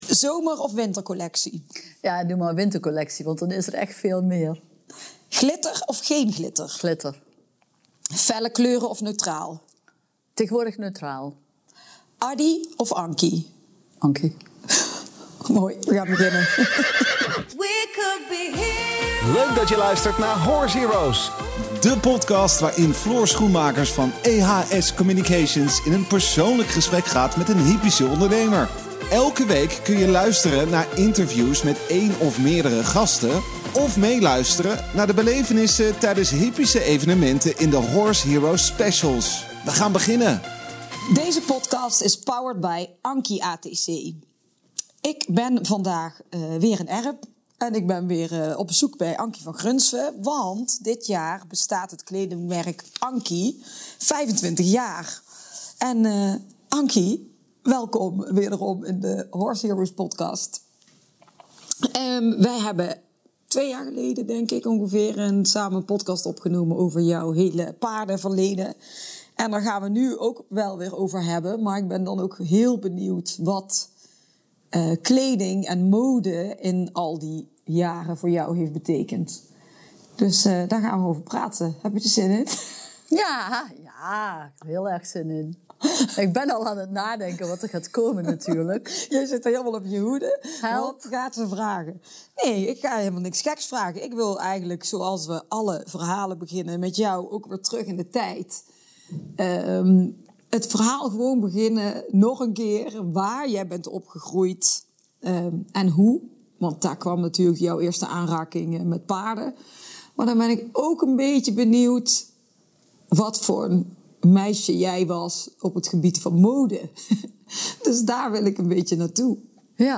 Ze ontmoet hem achter het Witte-orgel in de Janskerk te Utrecht.